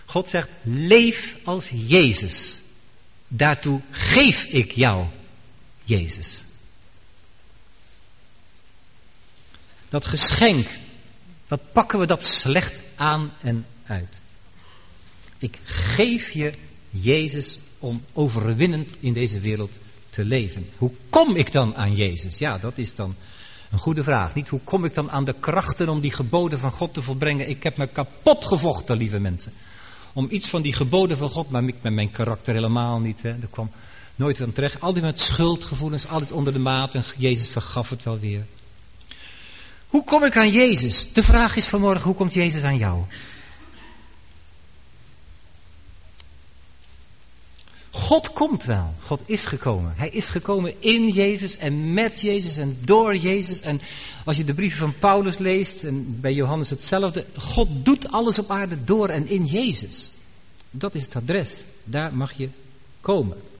Audio beschikbaarVan de spreekbeurt is een opname gemaakt en die bestaat uit bestanden: twee geluidsbestanden en een speellijst.